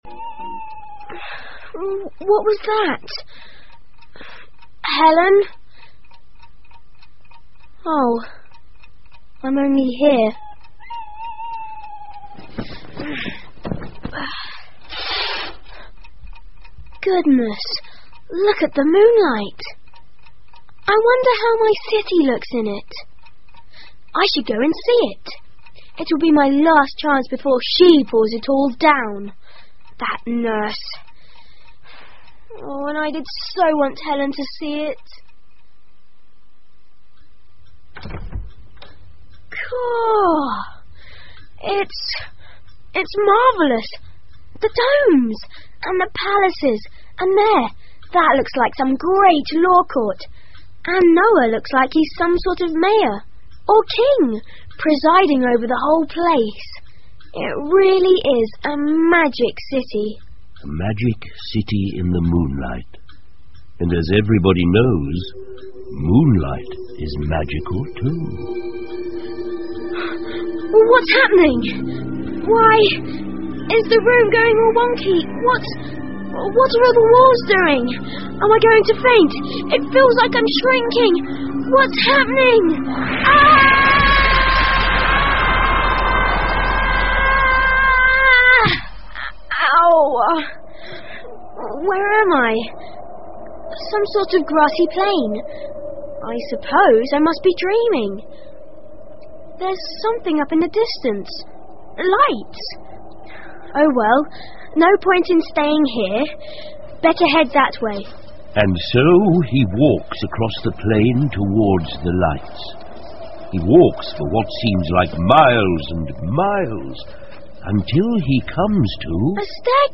魔法之城 The Magic City by E Nesbit 儿童广播剧 5 听力文件下载—在线英语听力室